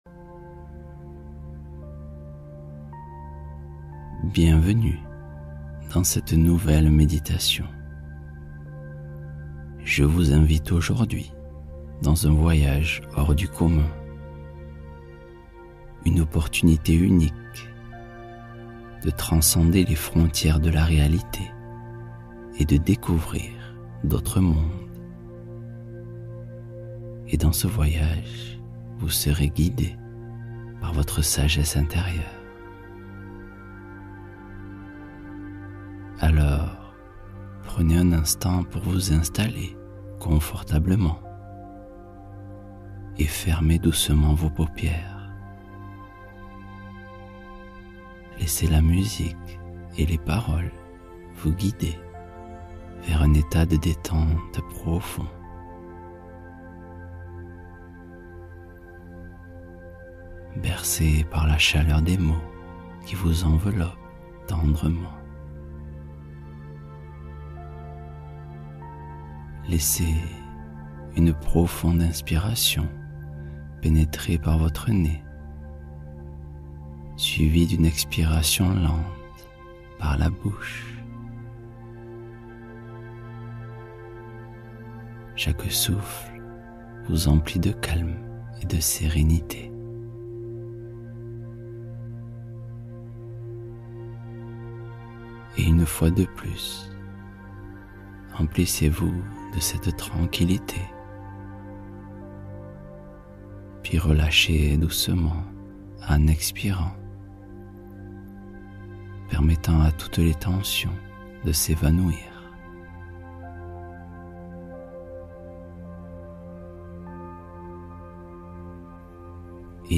Connexion intuitive — Relaxation douce pour rencontrer le guide intérieur